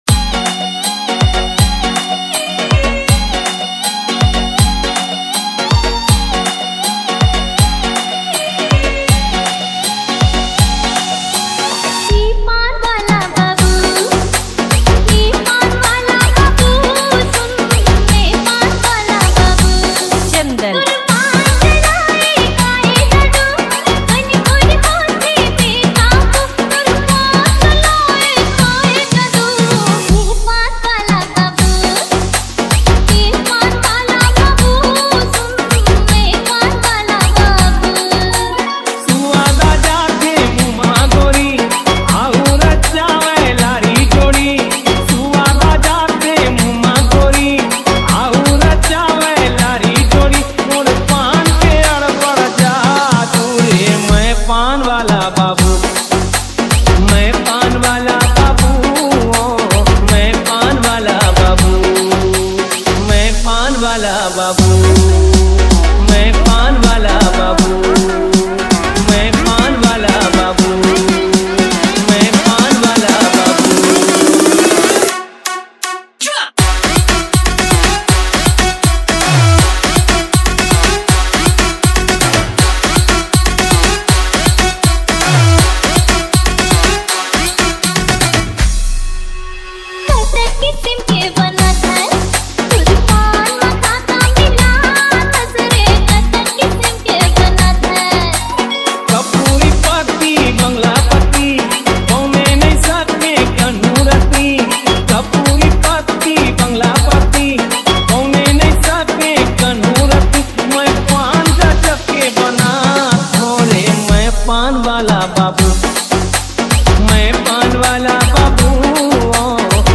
CG ROMANTIC DJ REMIX